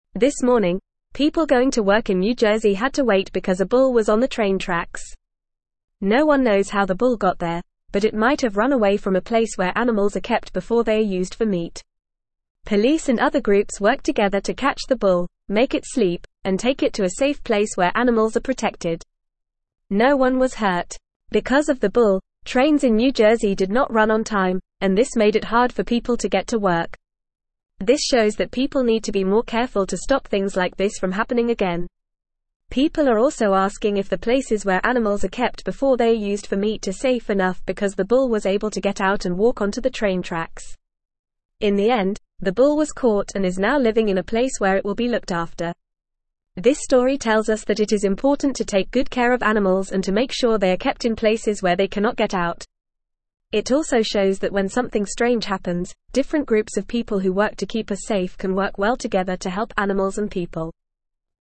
Fast
English-Newsroom-Lower-Intermediate-FAST-Reading-Bull-on-Train-Tracks-Causes-Trouble-Gets-Help.mp3